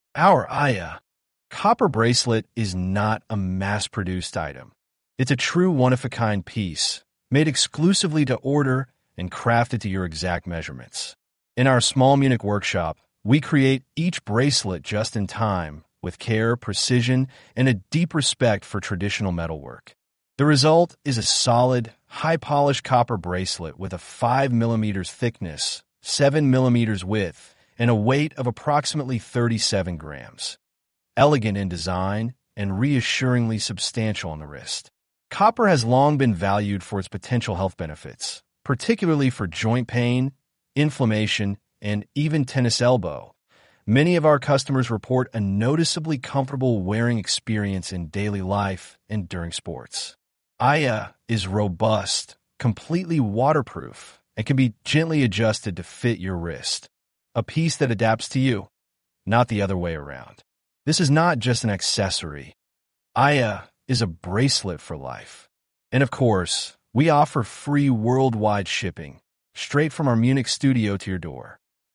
Aja-engl-rttsreader.mp3